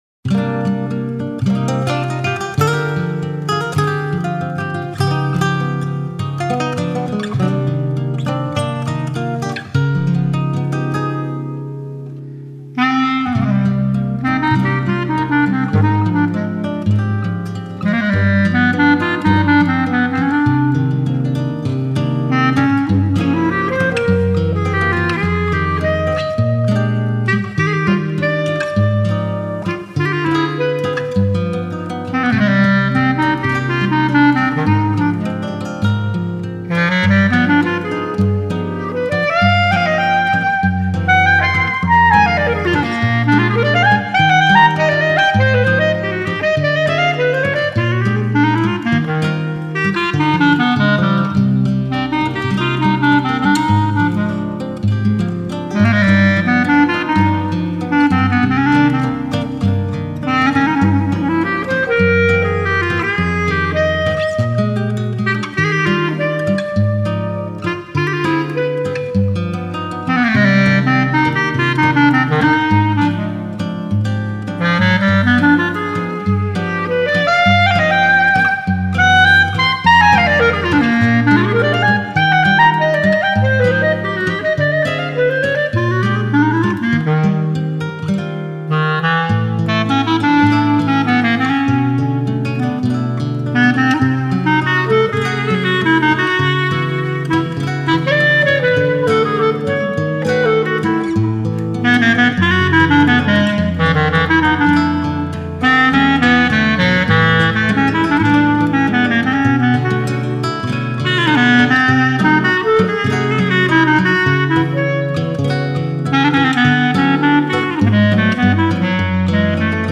2022   06:02:00   Faixa:     Instrumental